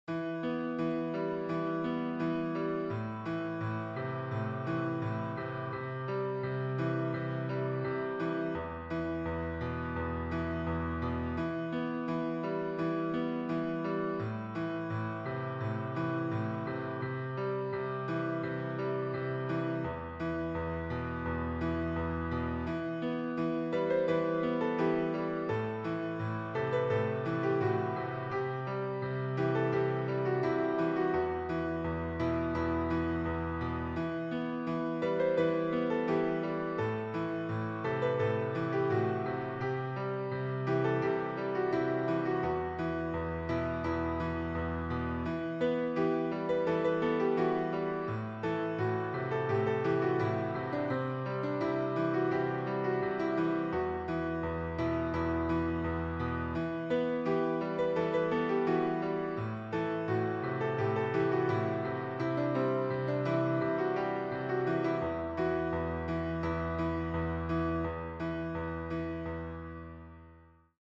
alternativ musiqi qrupu